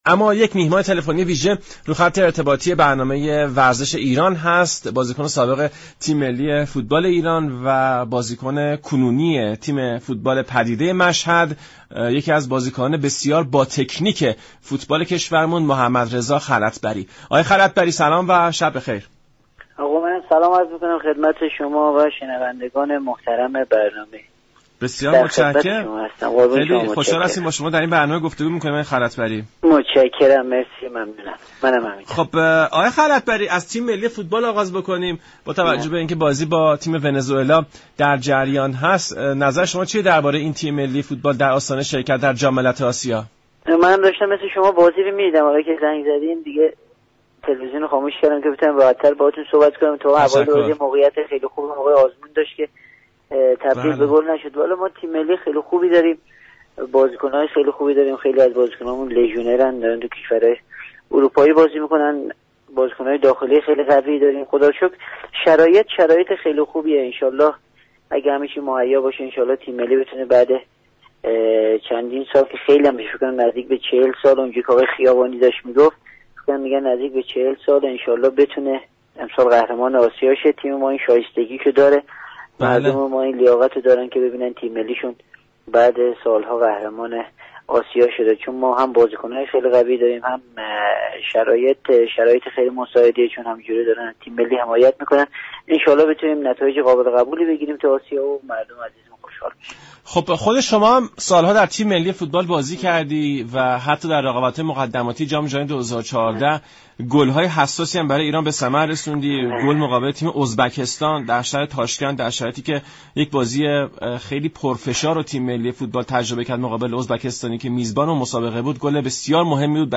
بازیكن سابق تیم ملی ایران و بازیكن كنونی تیم فوتبال پدیده مشهد در گفت و گو با رادیو ایران گفت